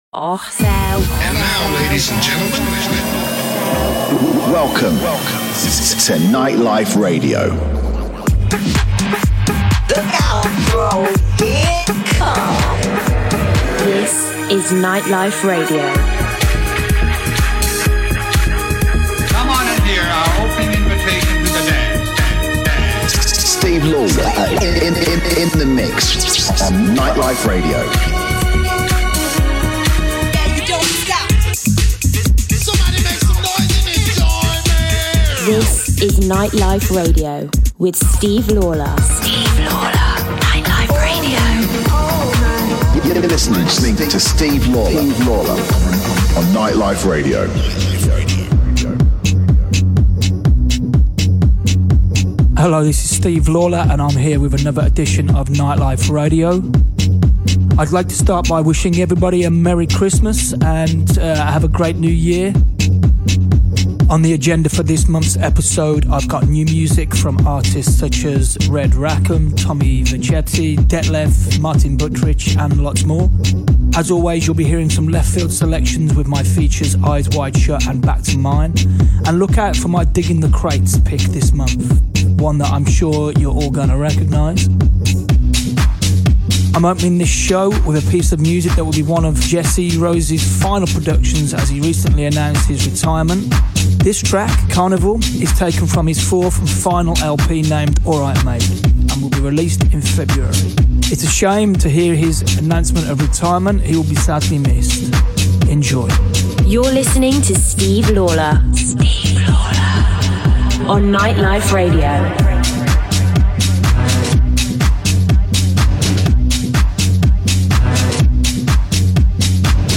Live Set